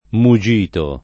DOP: Dizionario di Ortografia e Pronunzia della lingua italiana
muggito [ mu JJ& to ] s. m.